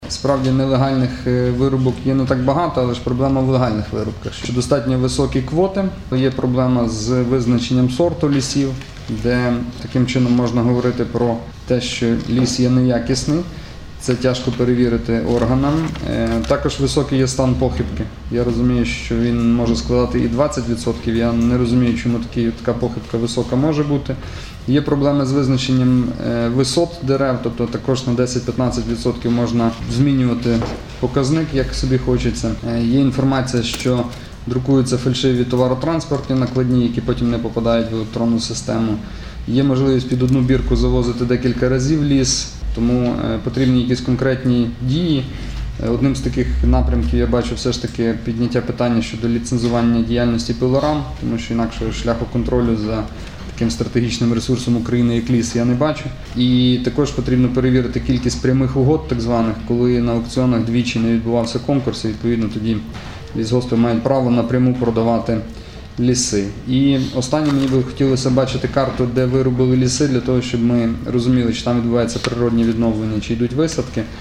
Голова Львівської облдержадміністрації Маркіян Мальський ініціював створення карти вирубки лісів Львівської області, аби мати розуміння, де потрібно проводити лісовідновлення та висадку. Про це очільник області повідомив сьогодні, 30 липня, під час апаратної наради.